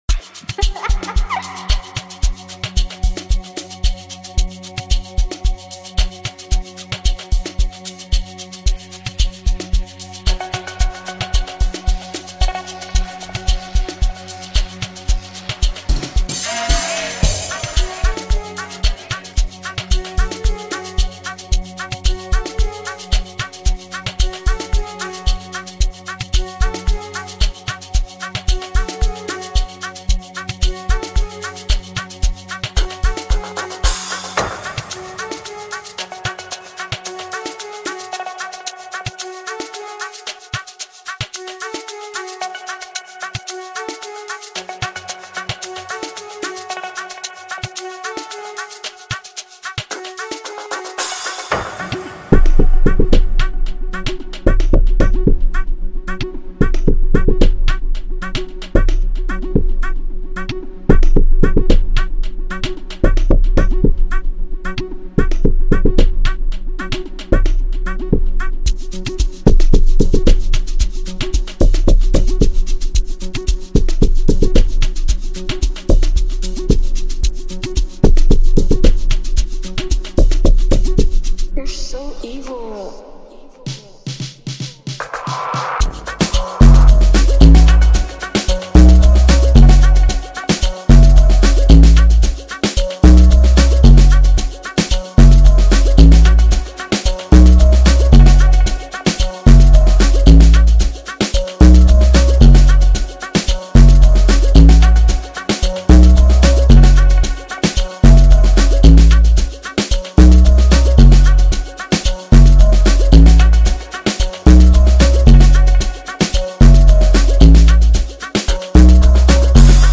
Home » Amapiano » Gqom » Lekompo
a trendy and most recent South African Amapiano song